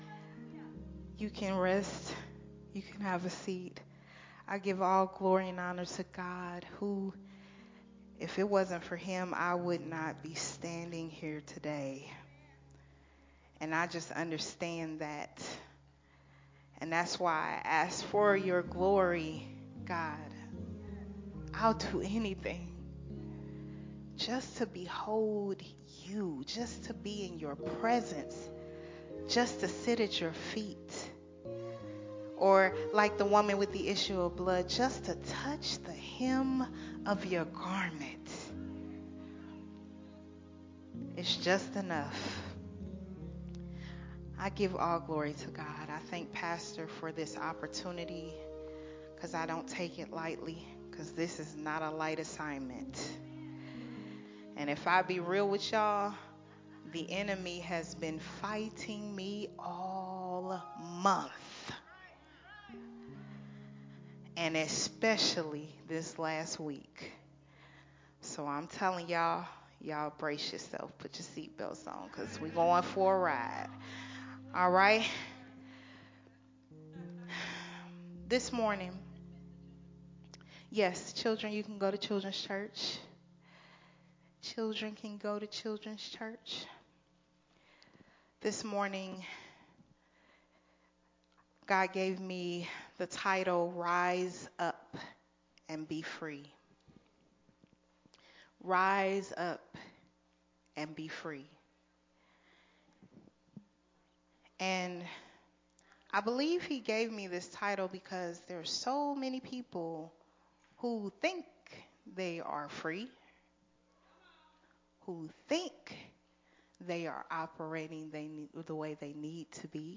recorded at Unity Worship Center on September 25, 2022.